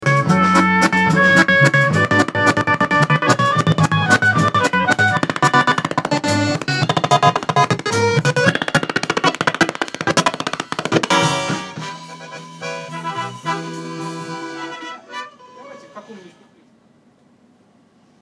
трески в behringer ddx3216, помогите понять в чем дело
есть сэмпл записи оркестра.
особенность в чем: когда делаешь громко, то проявляются эти трески, делаешь тише - все ок. и дело не в том, что это клипы из-за пересечения нулевой отметки.